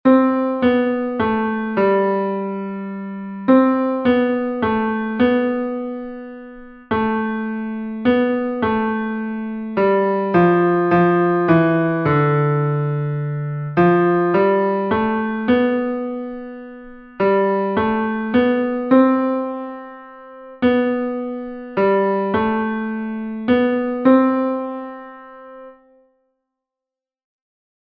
keeping the beat exercise 1